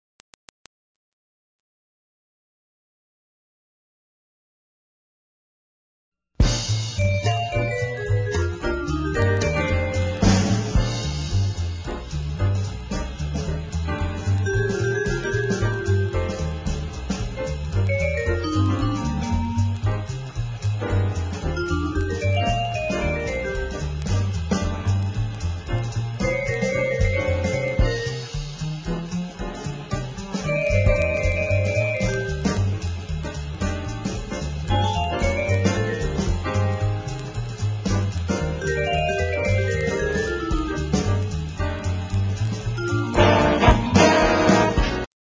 NOTE: Background Tracks 11 Thru 20